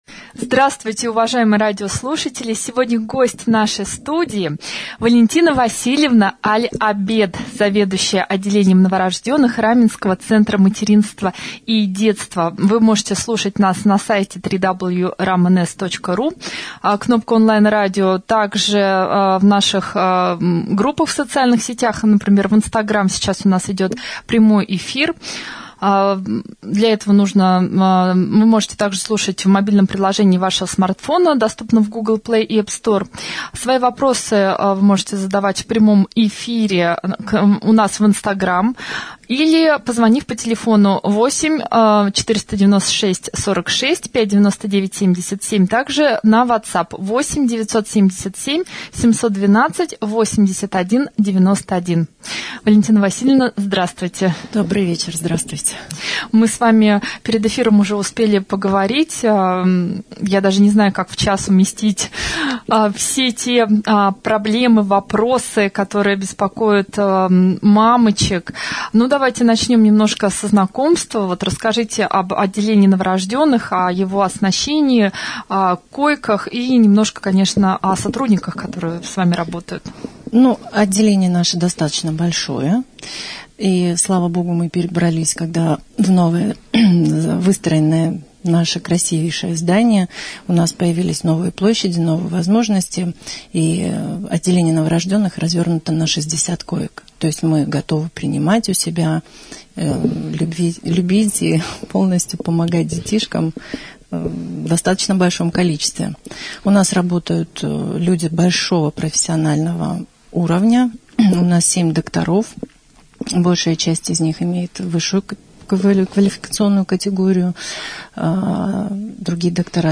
гостем прямого эфира на Раменском радио